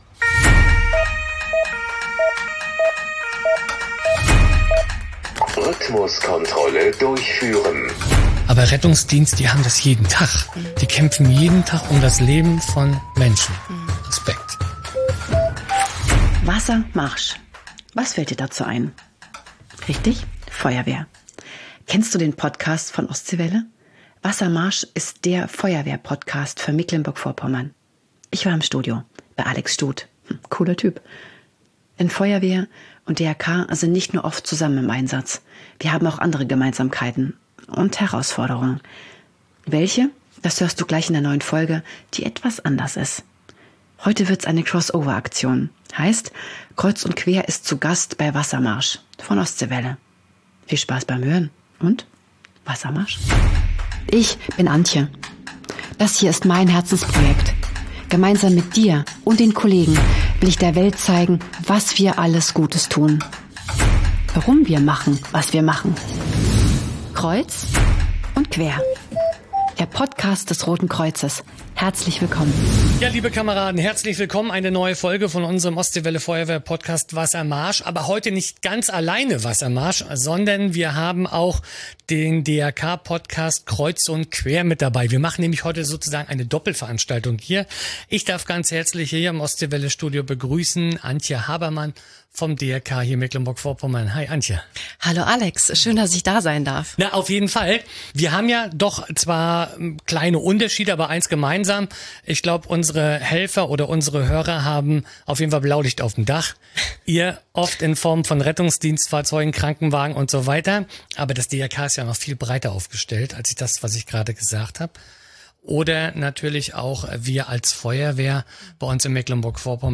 Ich war im Studio.